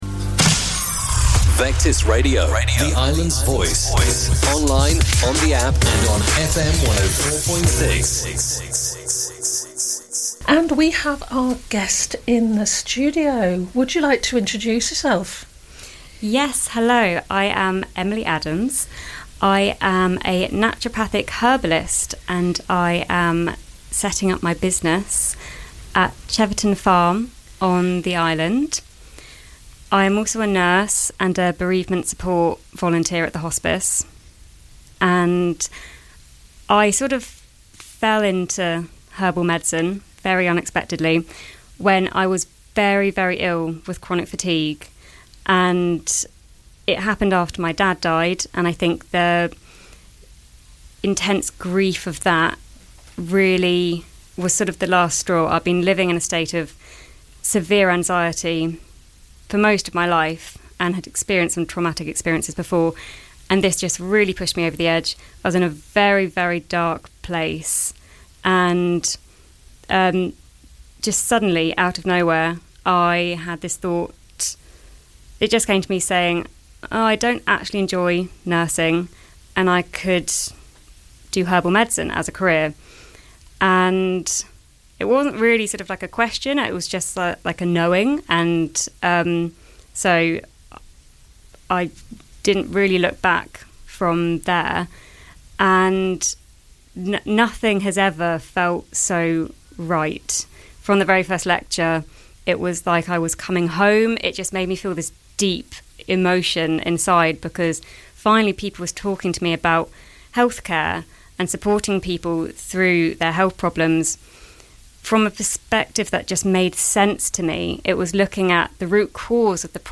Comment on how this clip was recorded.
came into the studio